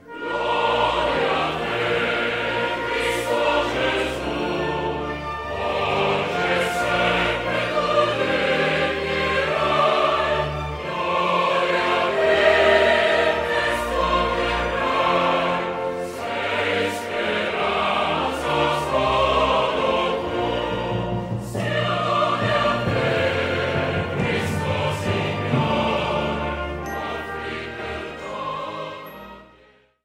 Un frammento dell'inno ufficiale